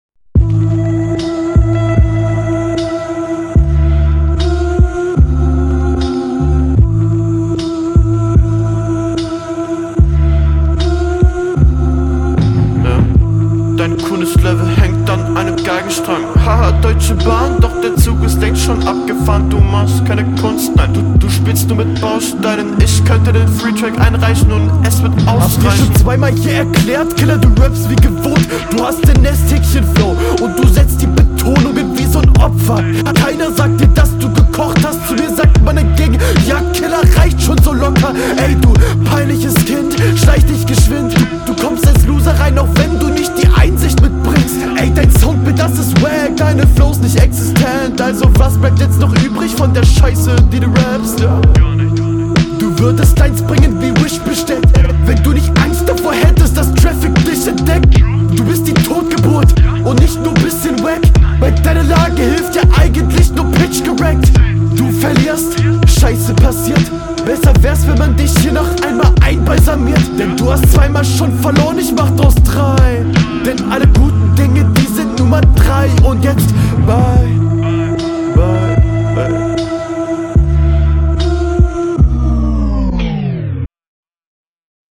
Kreativitätspunkte für das Soundbild, war mal was anderes.
Echt nice dich Mal auf nem langsamen Beat zu hören.